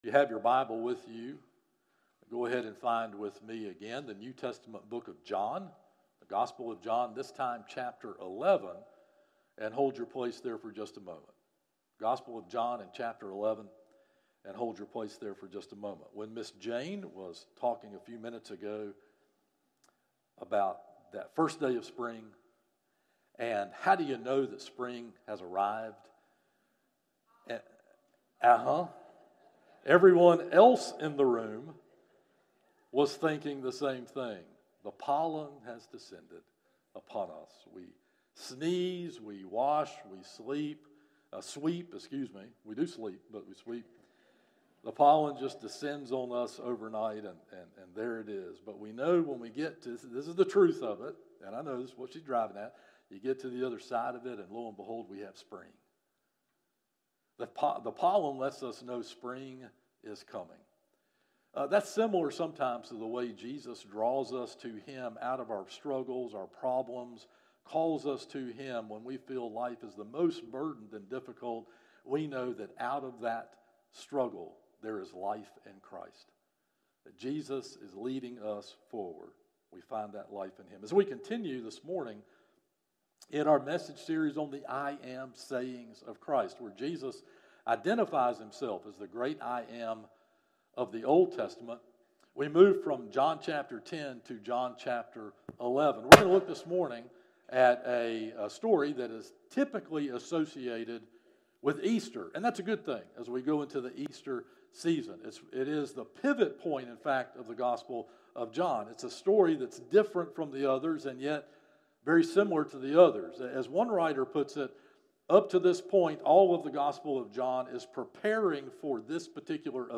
Morning Worship - 11am